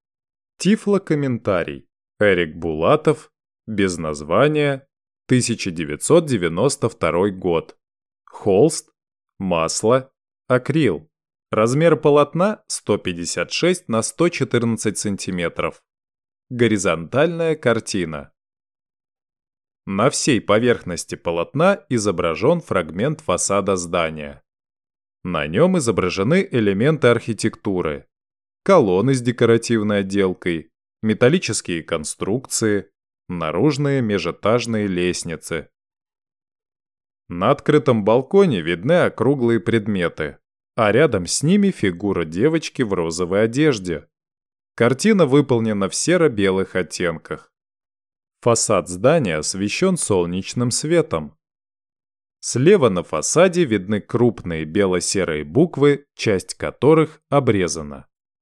Тифлокомментарий «Эрик Булатов- Без названия 1992 год»